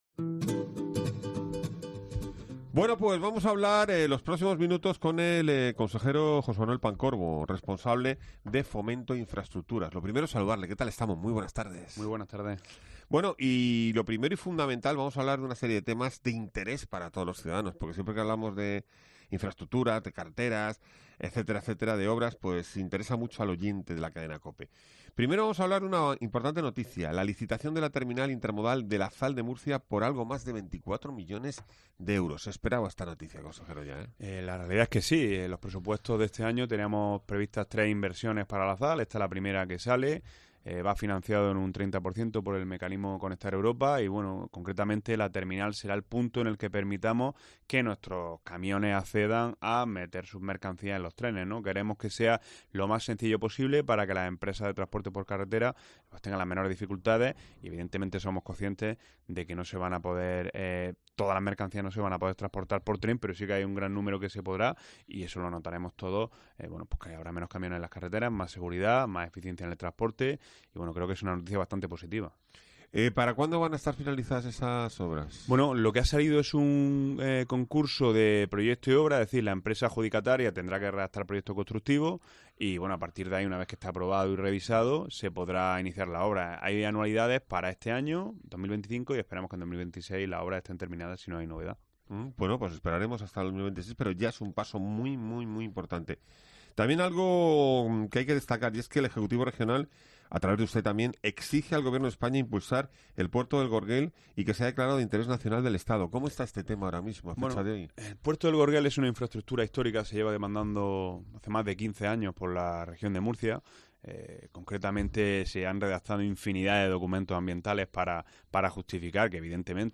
El Consejero de Fomento e Infraestructuras, José Manuel Pancorbo, habla de la ZAL de Murcia
El Consejero de Fomento e Infraestructuras ha visitado hoy los estudios de COPE MURCIA. José Manuel Pancorbo ha recordado que con la ZAL de Murcia, gran parte de los camiones de la región podrán meter sus mercancías en trenes.